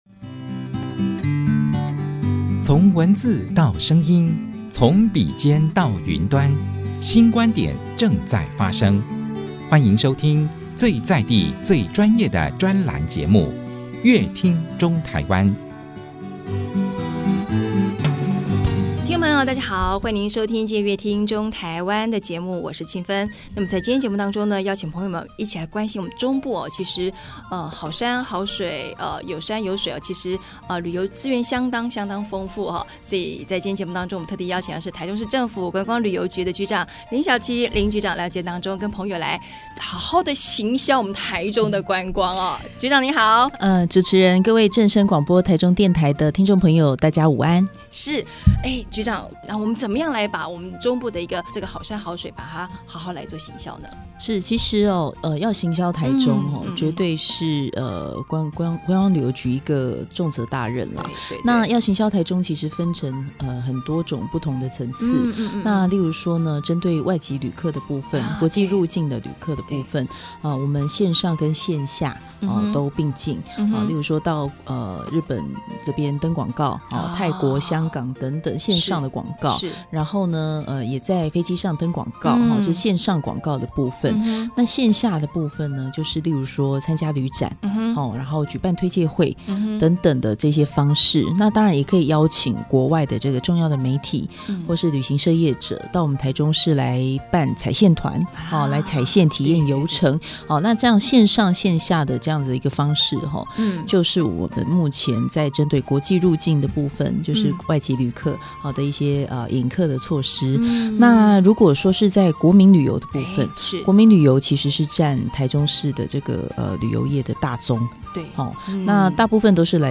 本集來賓：台中市政府觀光旅遊局林筱淇局長 本集主題：「國際接軌 行銷台中」 本集內容： 台中有豐富的觀光資源，吸引全台甚至是世界各地的朋友前來觀光，但要用什麼方法吸客呢?今天節目，特別邀請台中市政府觀光旅遊局林筱淇局長來和大家分享如何與國際接軌行銷台中的方法。